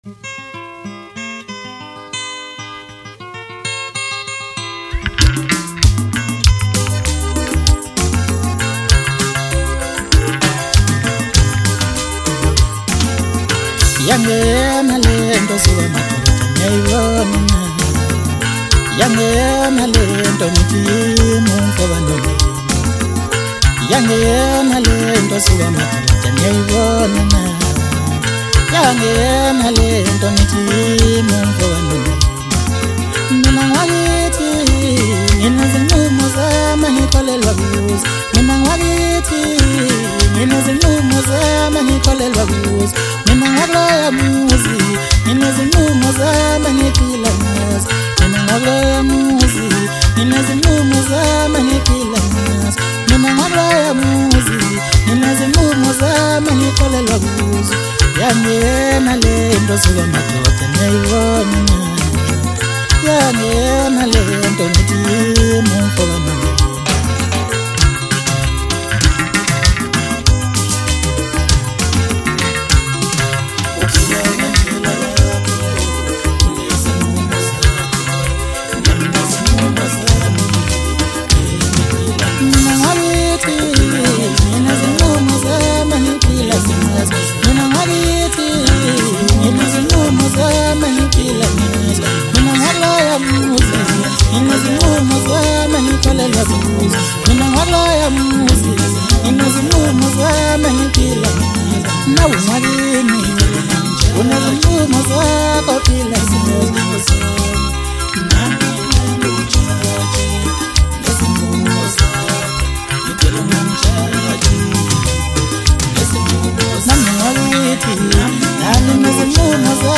Home » Maskandi
South African singer-songsmith